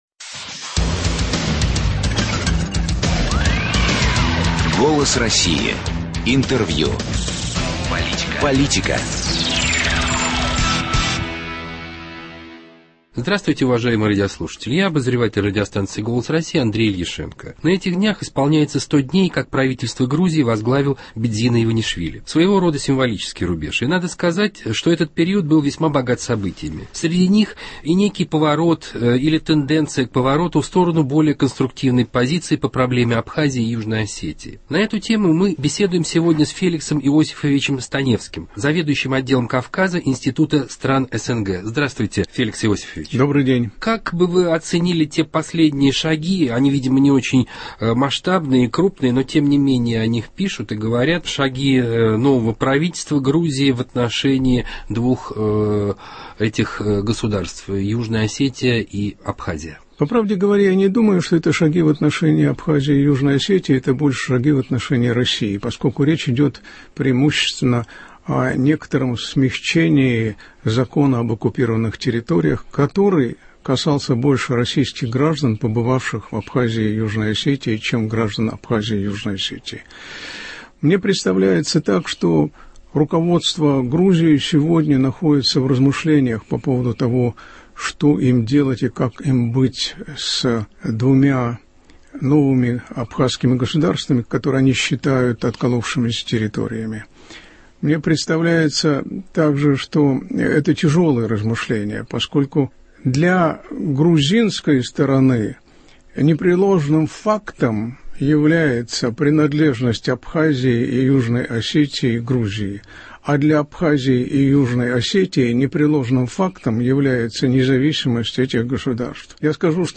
Скачать медиафайл Гость в студии